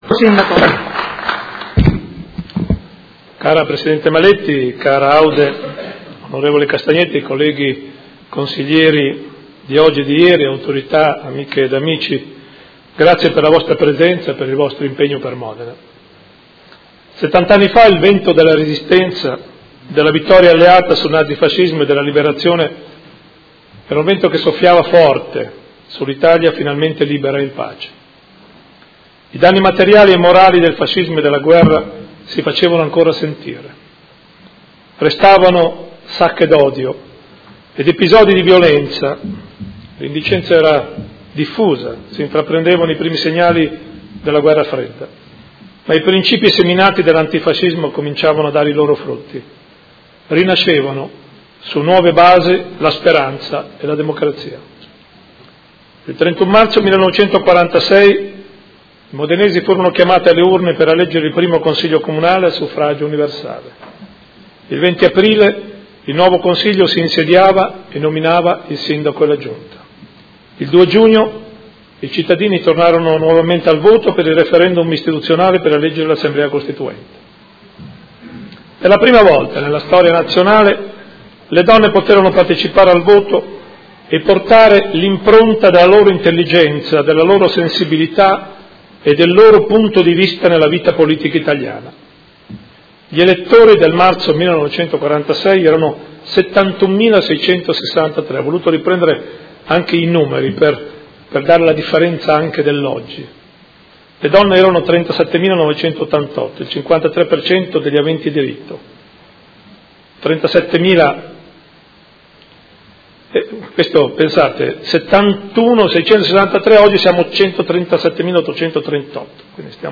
Seduta del 20/04/2016. Celebrazione del 70° dall'insediamento del primo Consiglio Comunale di Modena dopo il periodo fascista
Sindaco